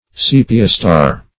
Search Result for " sepiostare" : The Collaborative International Dictionary of English v.0.48: Sepiostare \Se"pi*o*stare`\, n. [Sepia + Gr.